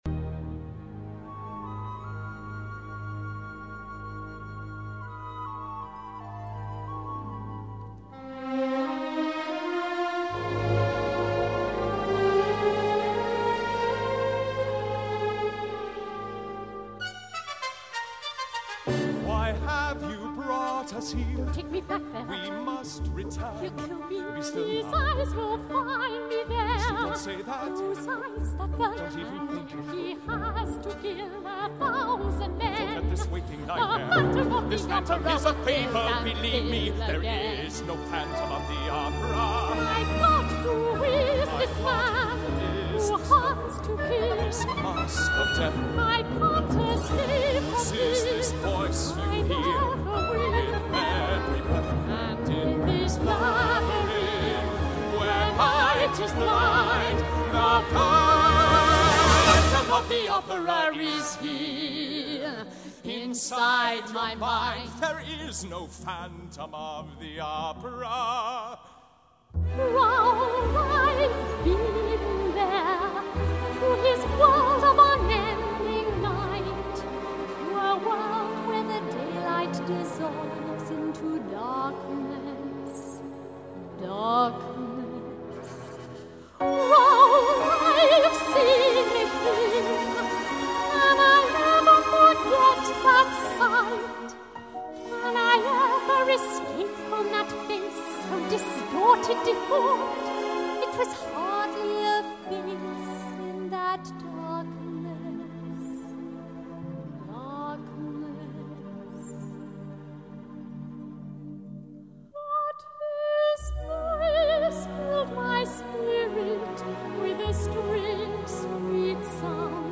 音乐类型：音乐剧